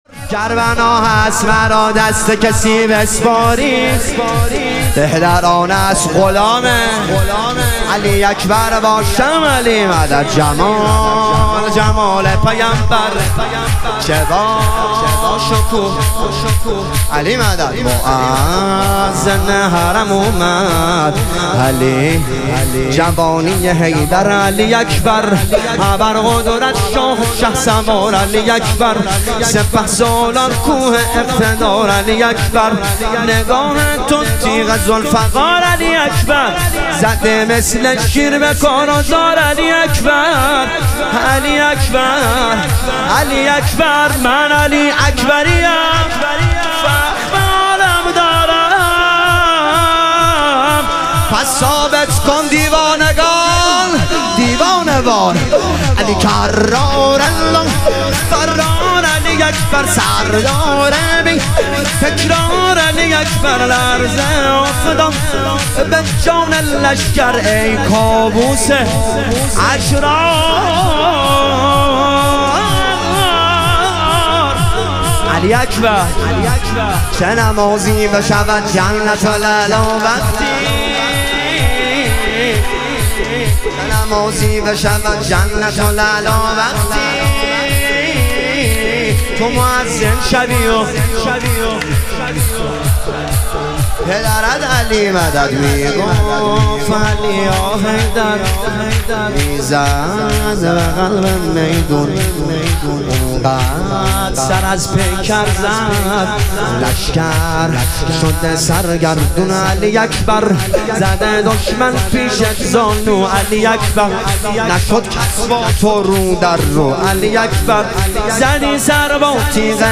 شهادت امام هادی علیه السلام - شور